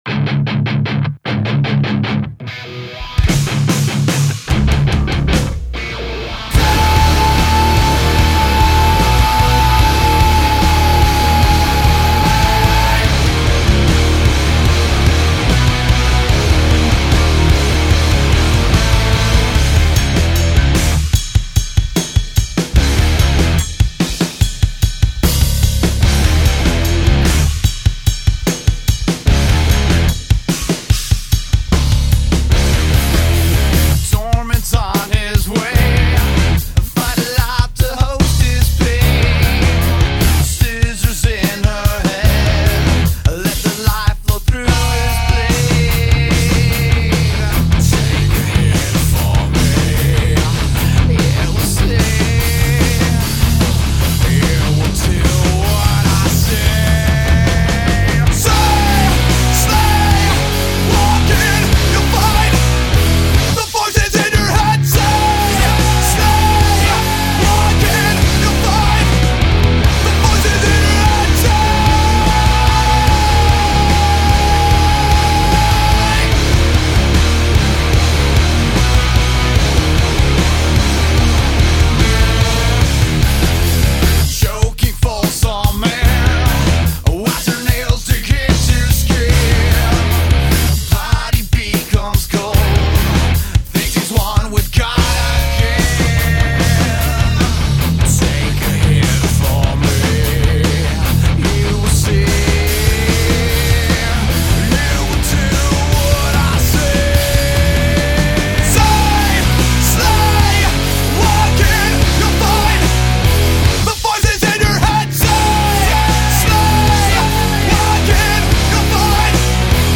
Bass and Vocals
Drums